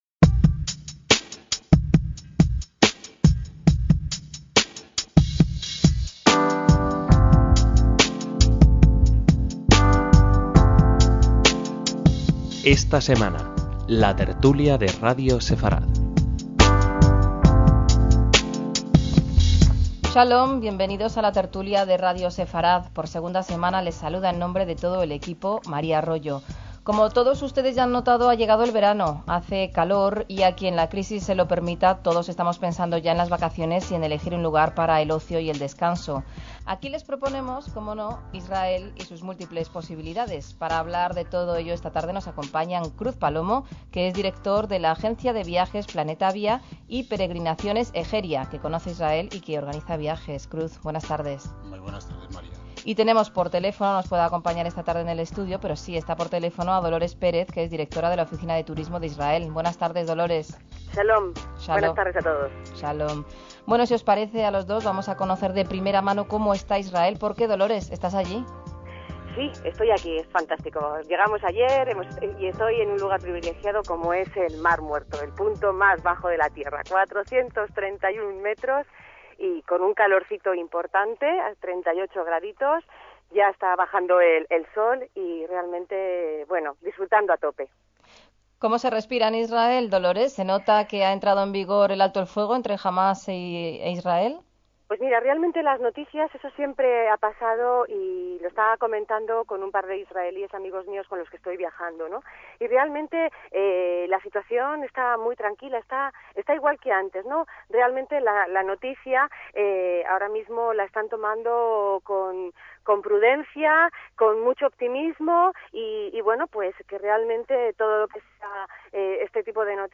En la tertulia que recuperamos el tema fue el turismo a Israel